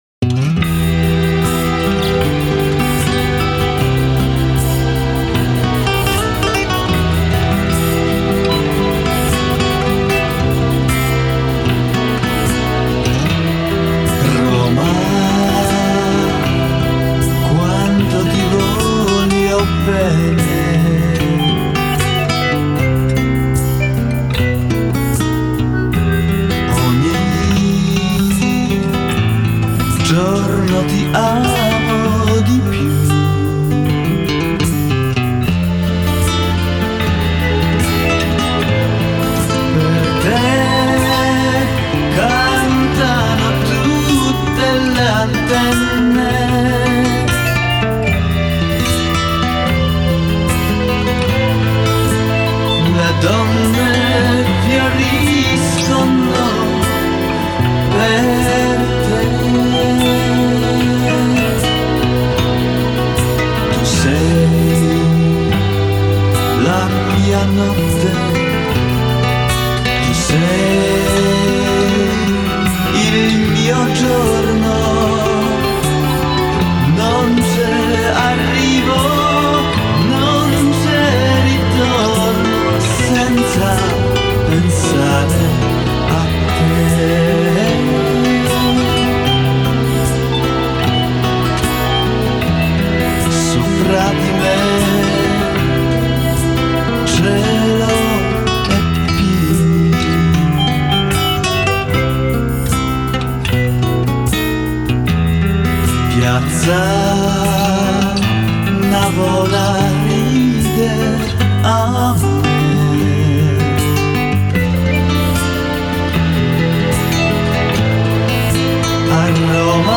мелодичных композиций